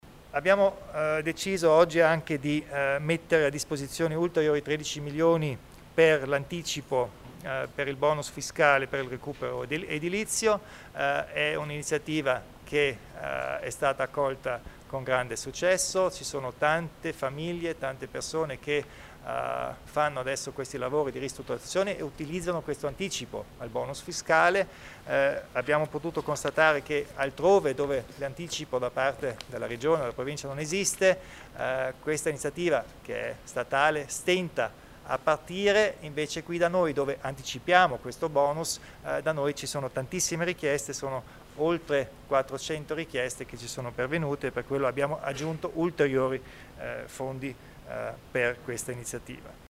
Il Presidente Kompatscher illustra le novità per il recupero edilizio